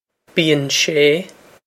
bíonn sé bee-on shay
Pronunciation for how to say
bee-on shay